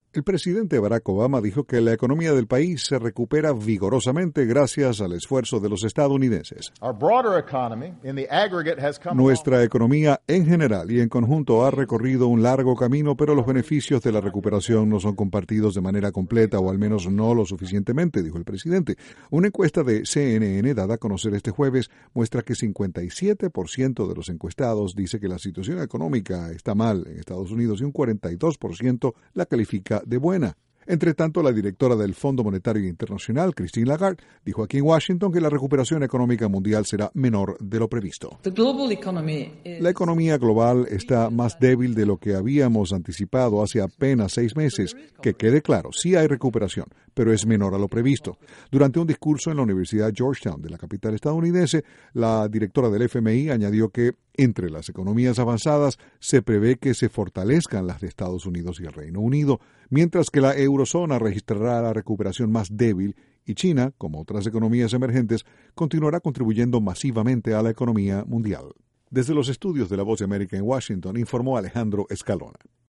Mientras el presidente Barack Obama dice que la economía de EEUU se recupera vigorosamente, la Directora del FMI, Christine Lagarde, sostiene que la recuperación de la economía, a nivel mundial, será menor de lo previsto. Desde la Voz de América, en Washington, informa